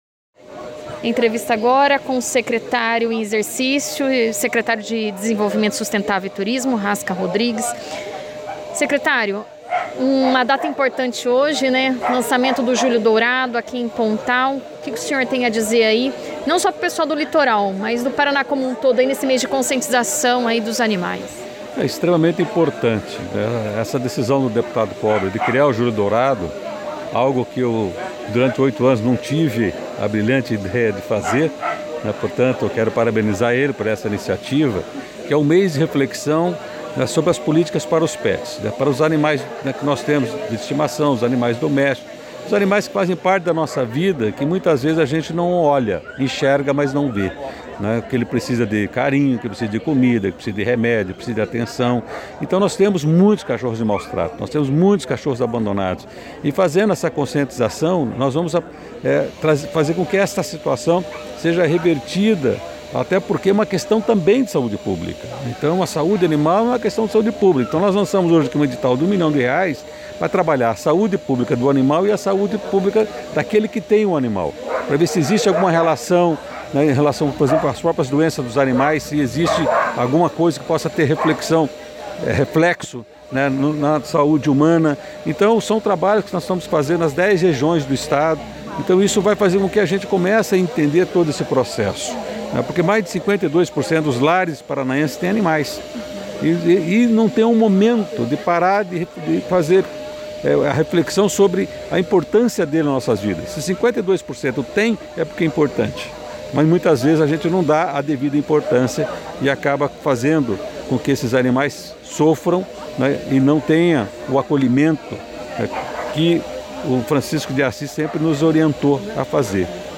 Raska Rodrigues, secretário em exercício de Desenvolvimento Sustentável e Turismo, falou com a nossa equipe de reportagem sobre o Julho Dourado.
Secretário-de-desenvolvimento-sustentável-e-turismo-fala-sobre-o-Julho-Dourado-online-audio-converter.com_.mp3